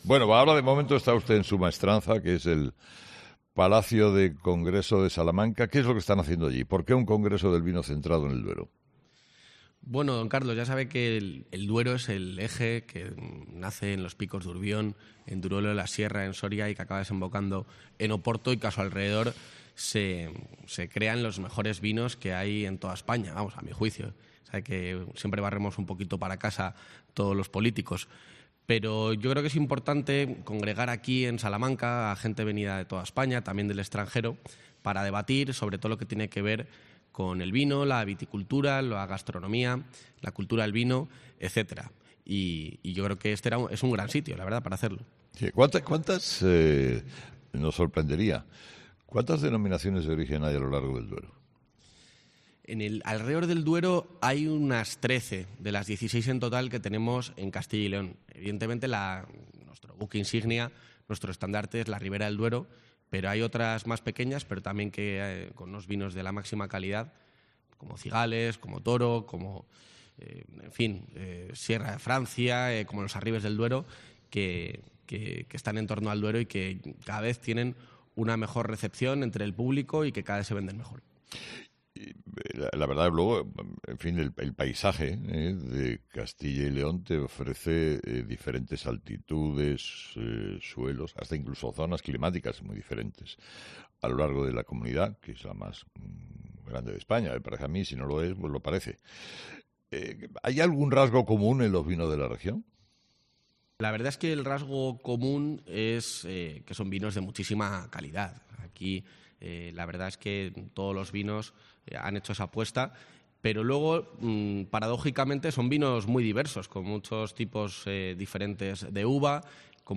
El vicepresidente de Castilla y León ha pasado por los micrófonos de 'Herrera en COPE' con motivo de la tercera edición del congreso internacional...